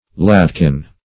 ladkin - definition of ladkin - synonyms, pronunciation, spelling from Free Dictionary Search Result for " ladkin" : The Collaborative International Dictionary of English v.0.48: Ladkin \Lad"kin\, n. A little lad.